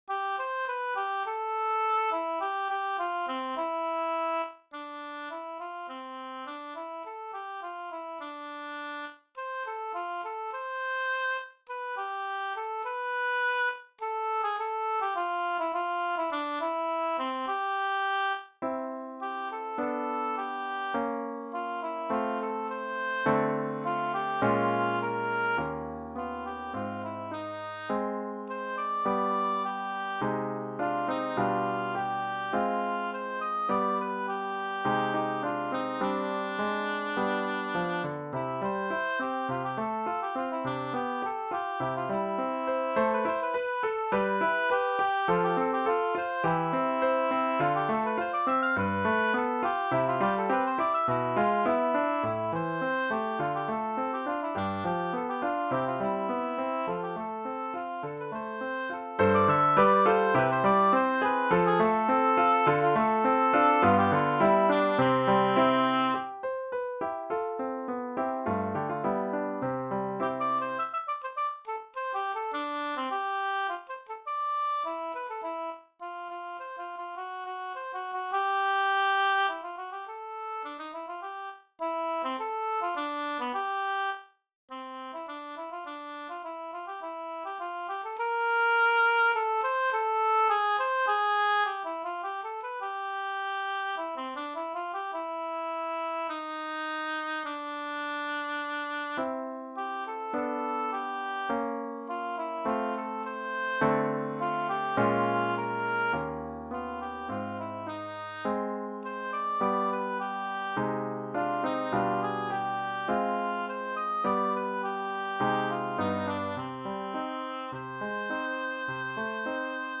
Pour hautbois et piano
Hautbois et piano